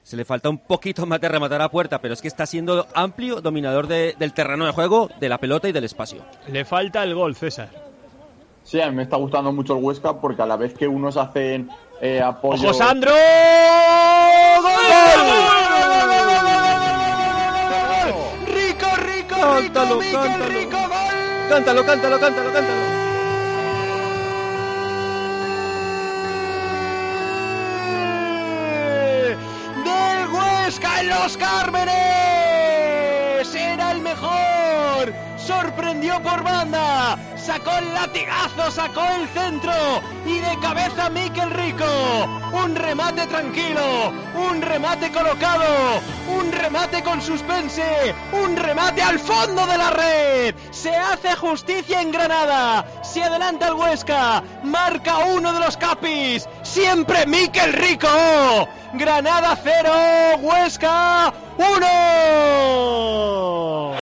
Narración Gol de Mikel Rico / Granada 0-1 Huesca